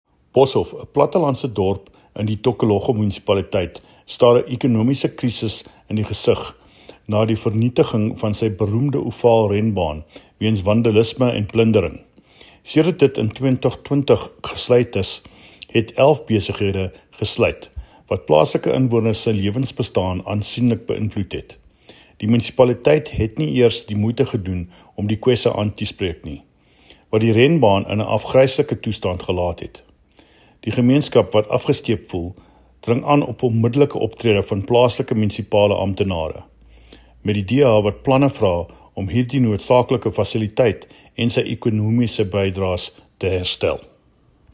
Afrikaans soundbites by David Mc Kay MPL and Sesotho soundbite by Jafta Mokoena MPL and images here,here,here,here,here and here